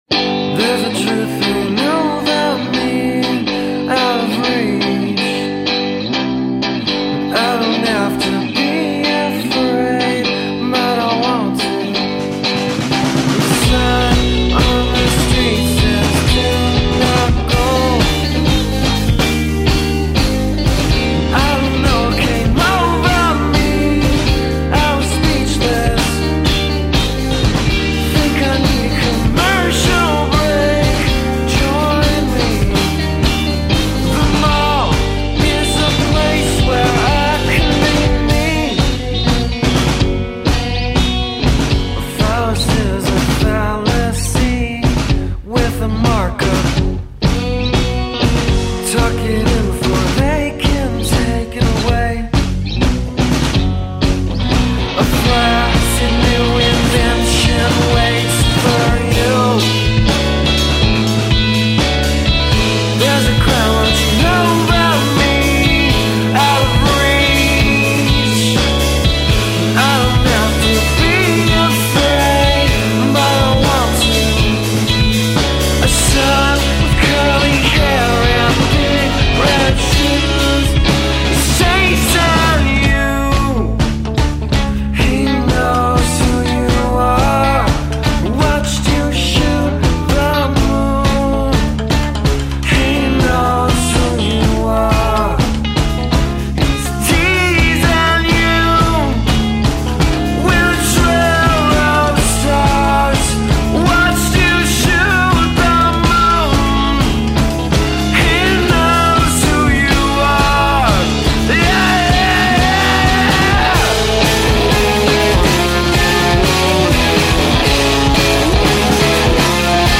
progressive rock band